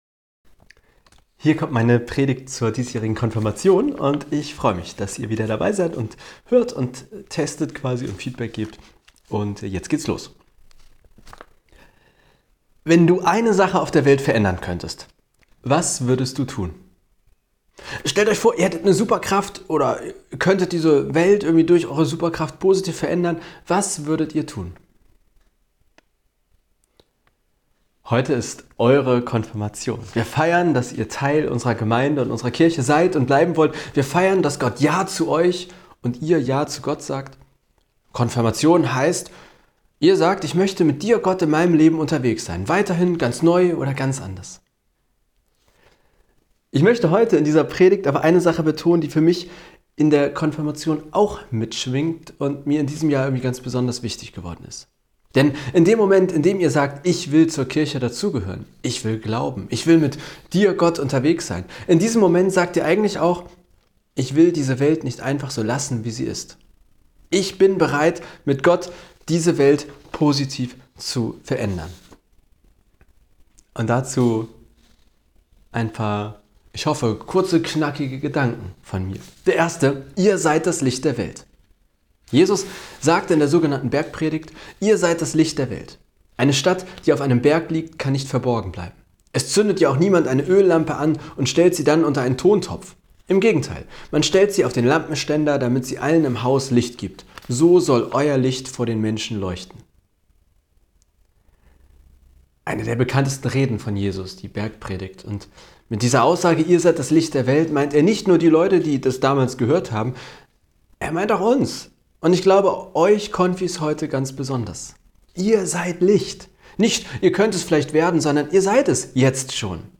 Konfipredigttest25.mp3